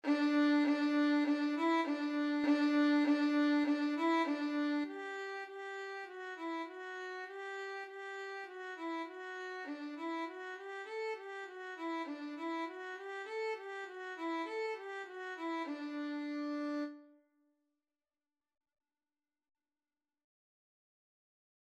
2/4 (View more 2/4 Music)
D5-A5
Violin  (View more Beginners Violin Music)
Classical (View more Classical Violin Music)